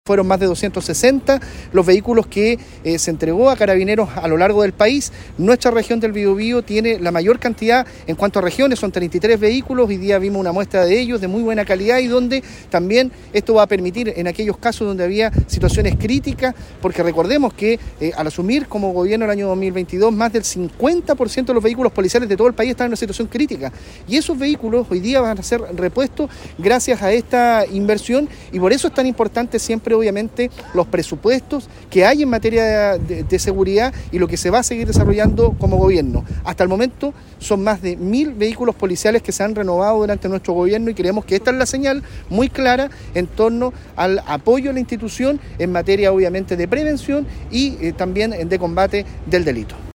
En una ceremonia efectuada en la Plaza Independencia de Concepción, autoridades regionales hicieron entrega formal de 33 nuevos vehículos policiales que serán distribuidos en distintas comunas de la Región, y destacaron que estos recursos logísticos reforzarán la base de la labor policial, los patrullajes, y la operatividad general de Carabineros en el territorio.